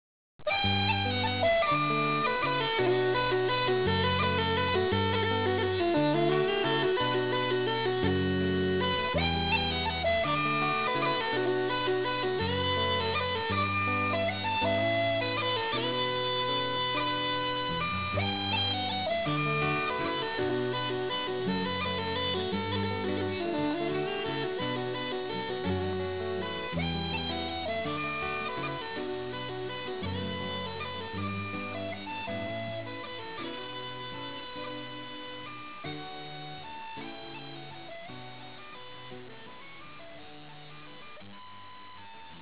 original in a traditional
mode with a strong percussive feel to it, creating
a new and lively interpretation of Irish music.